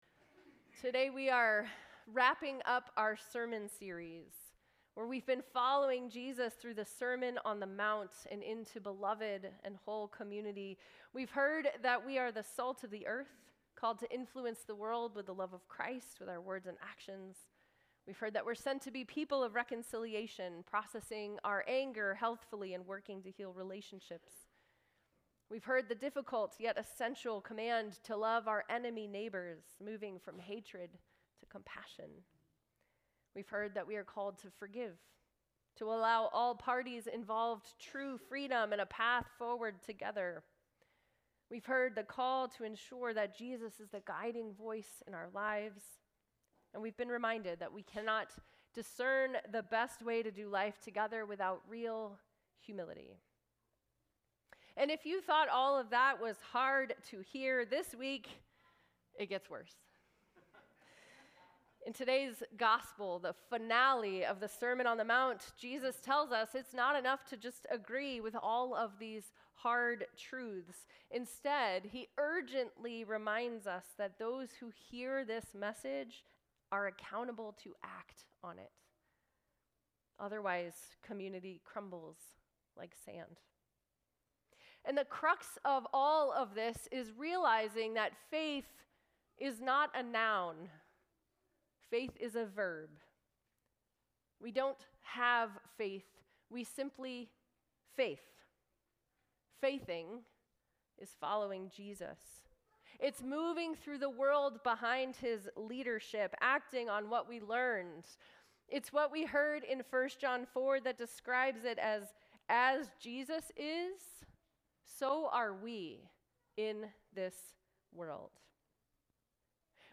Sermons | Good Shepherd Lutheran Church